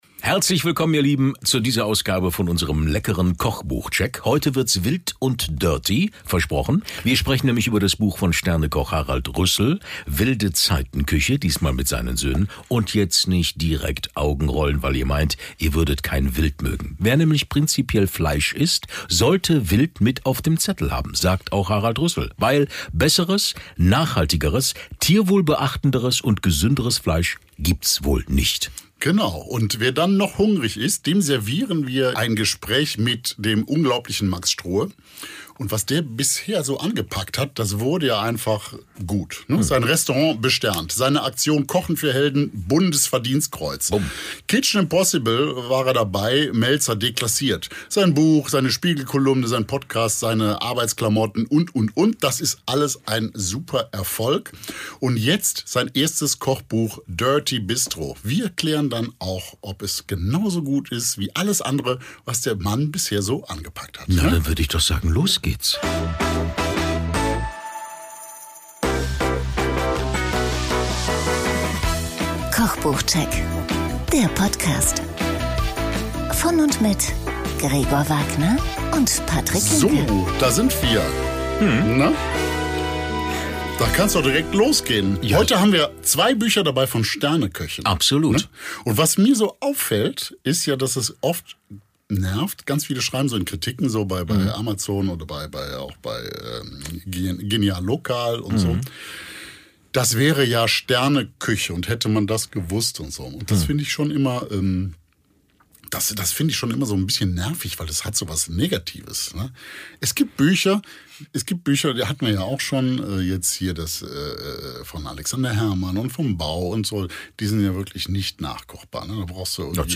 Das uns mehr: Im anschließendem Interview mit Max Strohe!